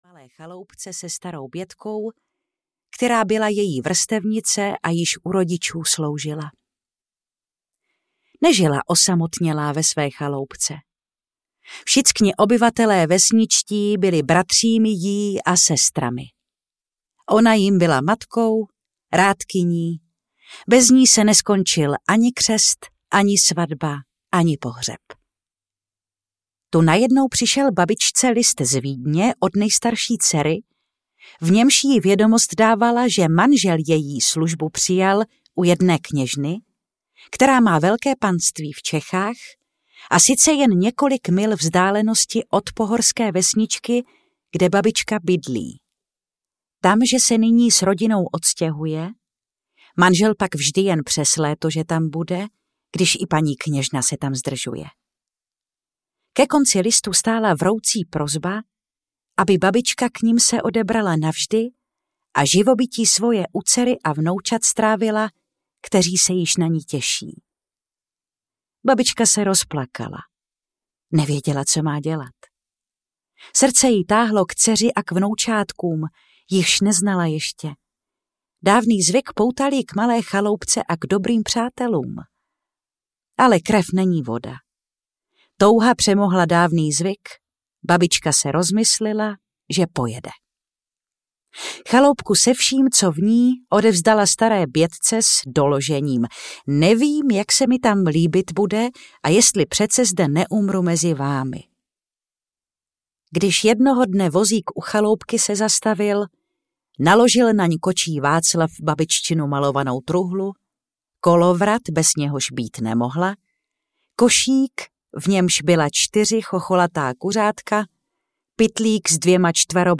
Babička audiokniha
Ukázka z knihy